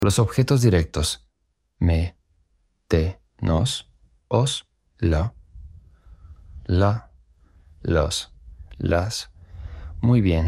Play the audio to hear how the direct object pronouns are pronounced.